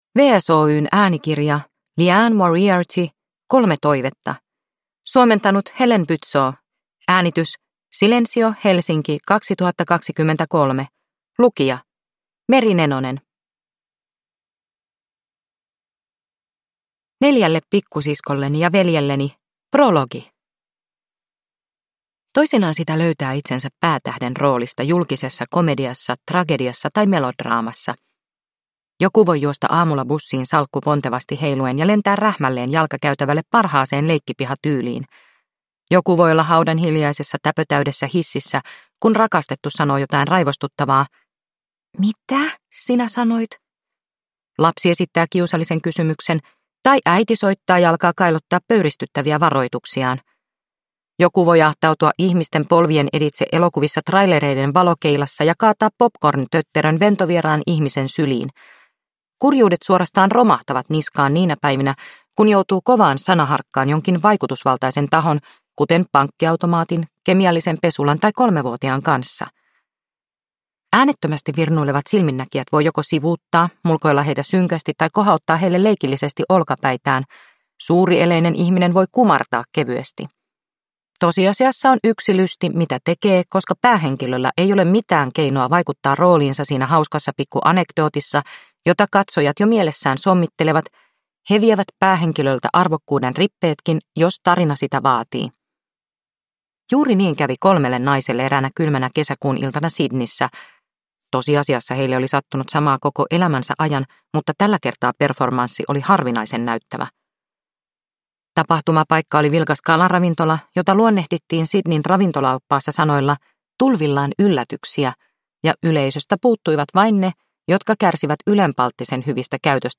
Kolme toivetta – Ljudbok – Laddas ner